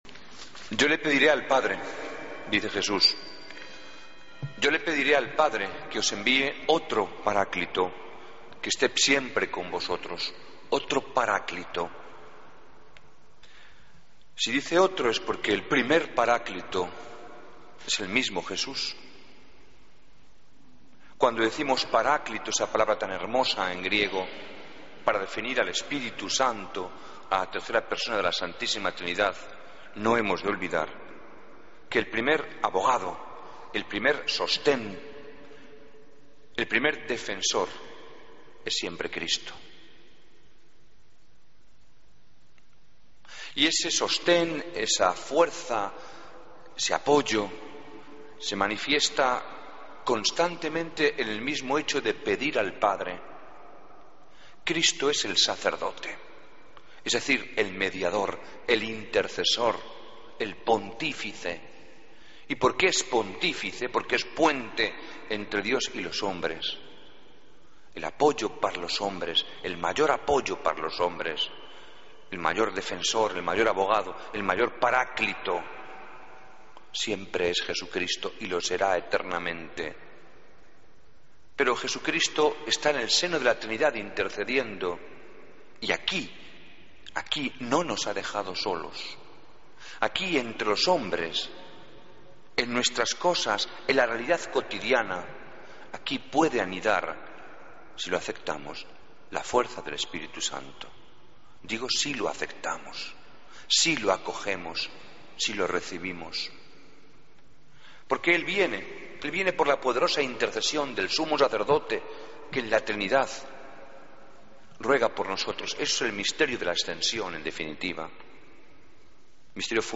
Homilía del Domingo 25 de Mayo de 2014